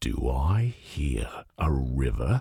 B_river1.ogg